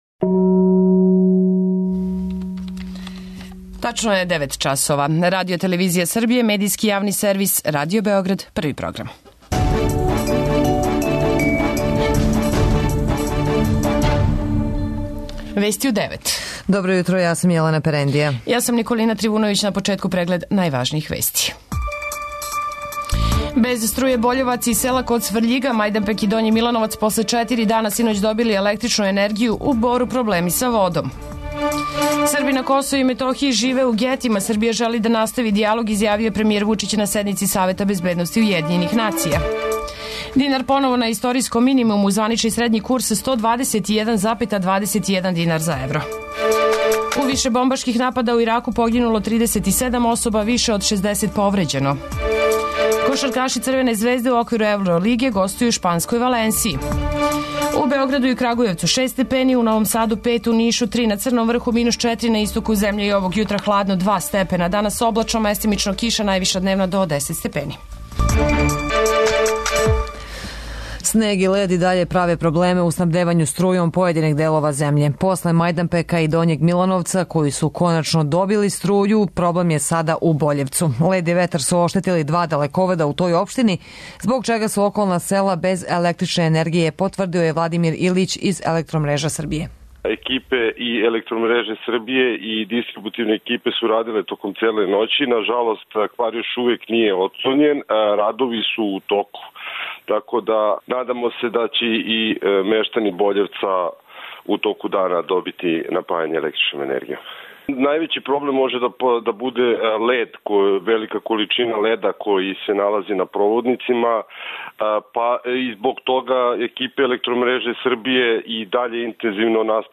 Уреднице и водитељке